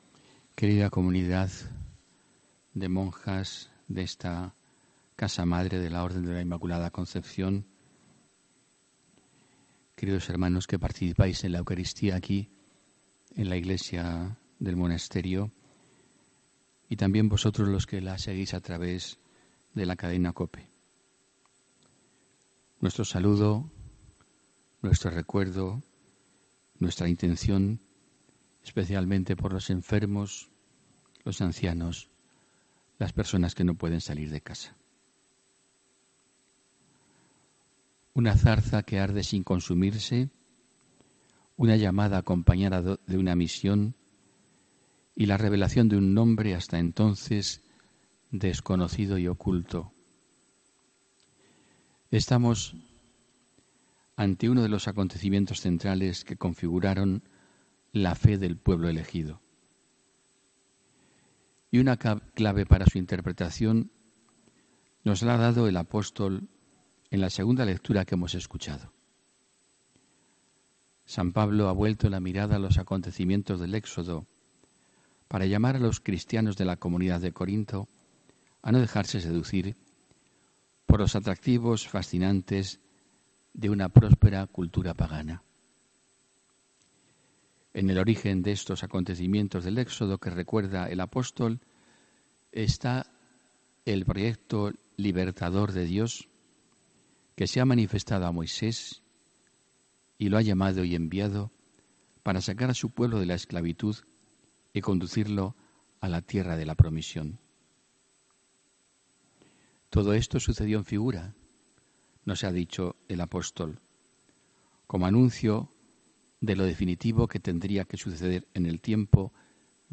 HOMILÍA 24 MARZO 2019